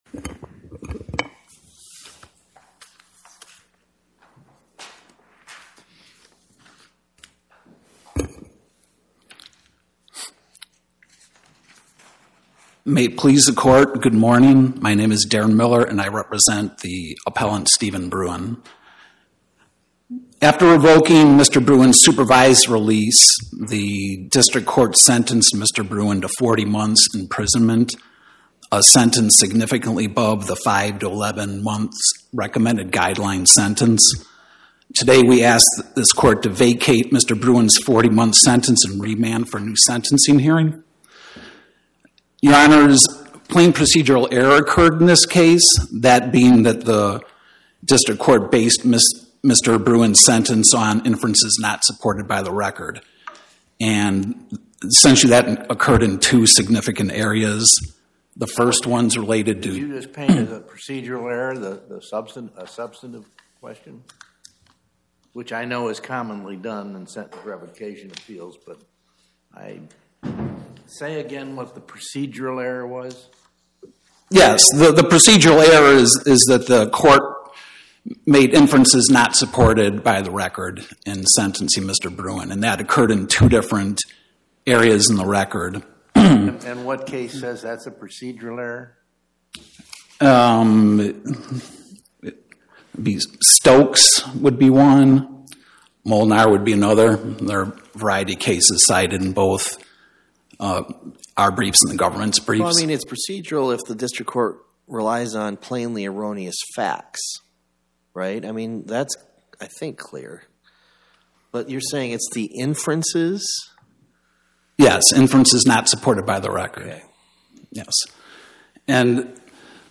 Oral argument argued before the Eighth Circuit U.S. Court of Appeals on or about 12/19/2025